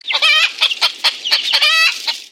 Сhicken - Курица (клушка)
Отличного качества, без посторонних шумов.
196_chicken.mp3